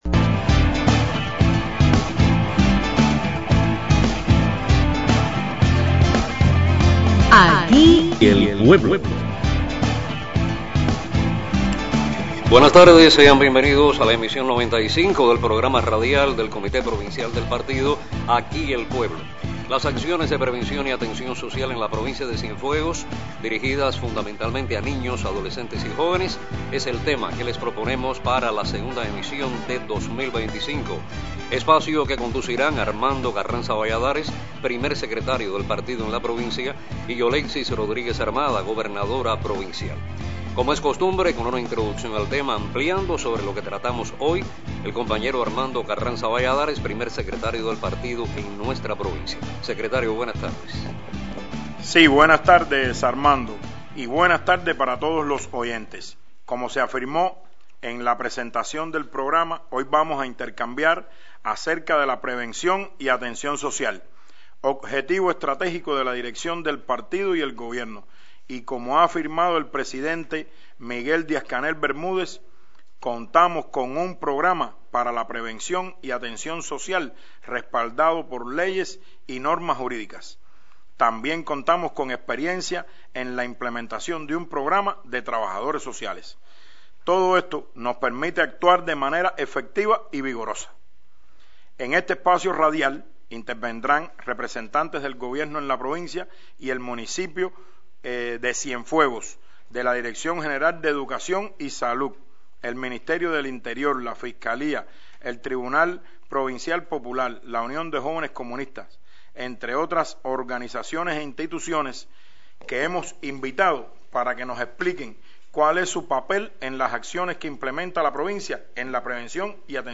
Las acciones de prevención y atención social con niños, adolescentes y jóvenes centraron el intercambio de la emisión de febrero del programa Aquí el pueblo, por Radio Ciudad del Mar, conducido por las máximas autoridades del Partido y el Gobierno en el territorio sureño.